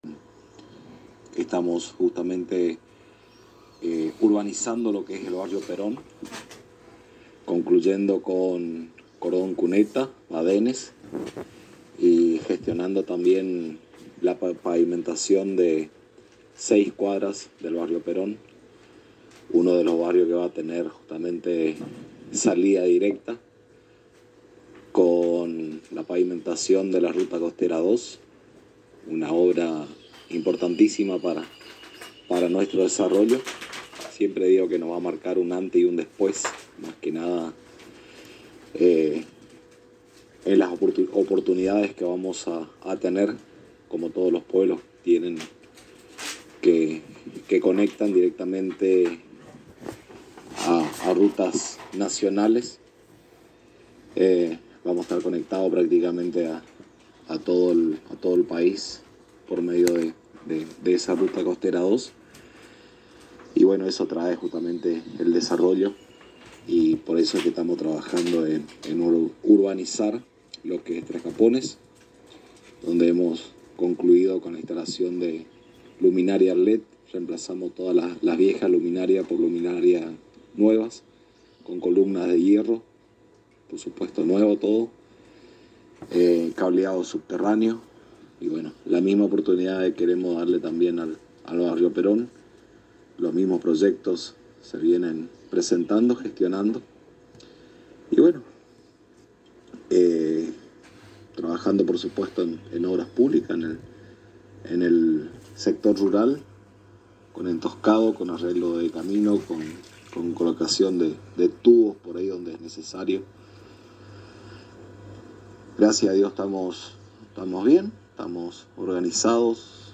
El intendente de Tres Capones, Ramón Gerega en diálogo con la ANG resaltó la importancia de la Ruta Provincial Nº 2 que traerá progreso y conectividad con el País y países limítrofes. Ante esto el Municipio está trabajando en la Urbanización de los Barrios de la localidad, en este caso en particular en el Barrio Perón donde se están realizando empedrado y cordón cuneta. El jefe comunal expresó que las obras se realizan con orden y responsabilidad.
Intendente de Tres Capones Ramón Gerega